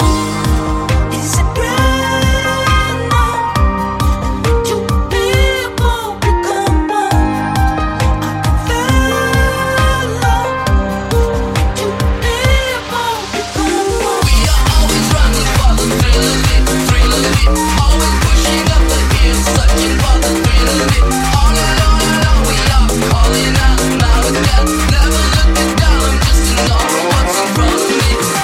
mashup
Genere: multi genere, mashup, successi, remix